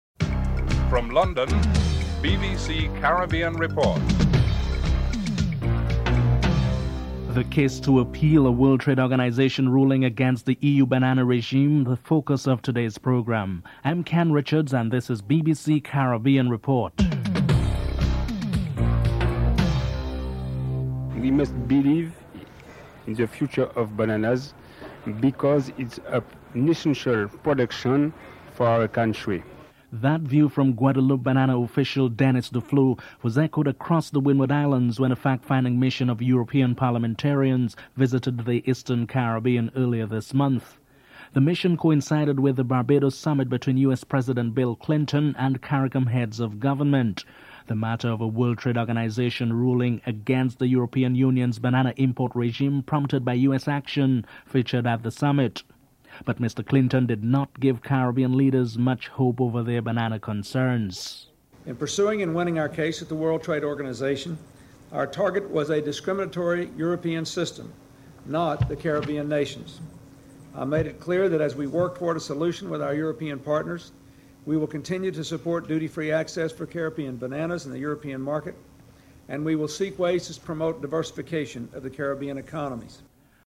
4. Commissioner Franz Fischler looks at the implications of an appeal to the WTO ruling.
5. OECS Ambassador Edwin Laurent has the last word on ACP cooperation and the Caribbean stance on bananas. Ambassador Edwin Laurent is interviewed (14:28-15:22)